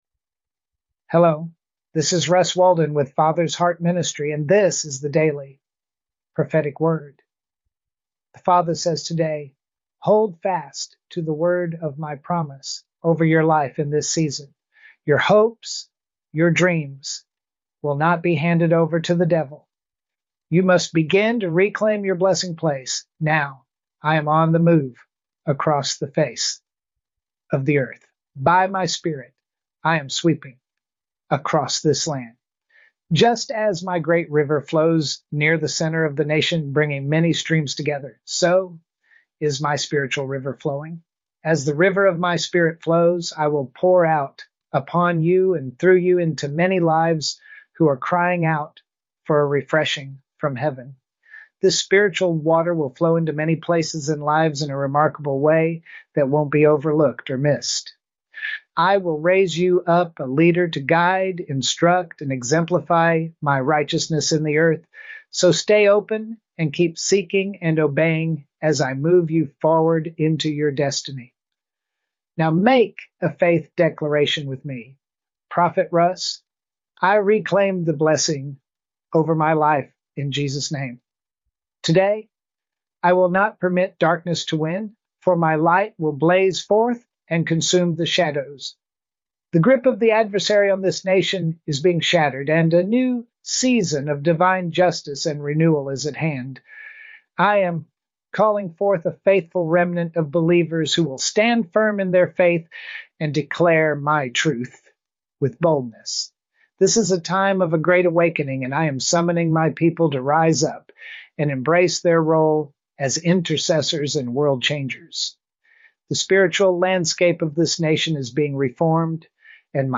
Daily Prophetic Word